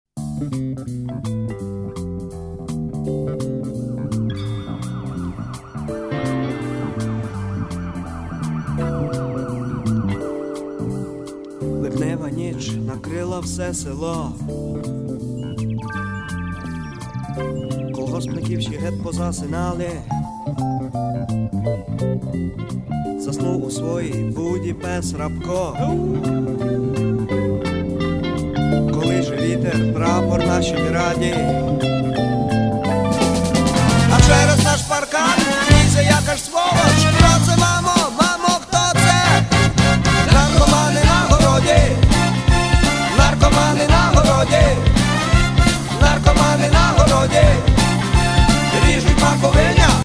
Rock (320)